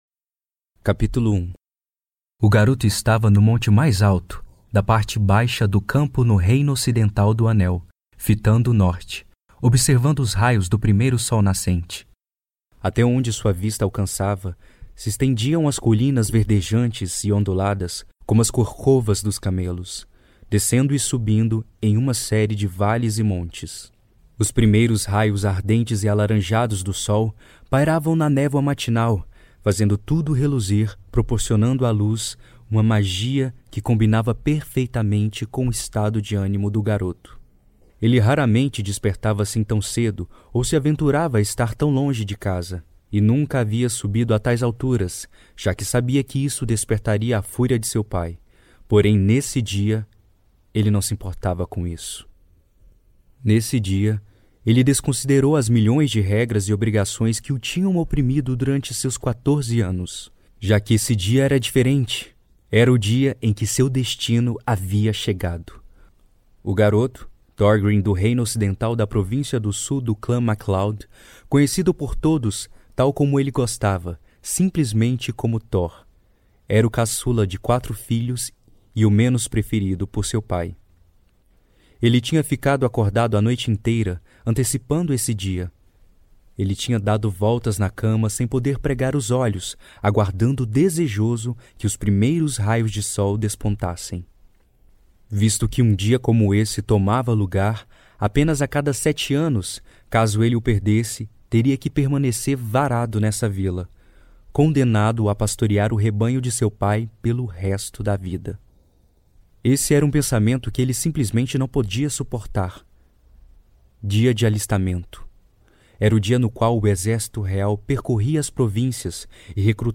Аудиокнига Em Busca de Heróis | Библиотека аудиокниг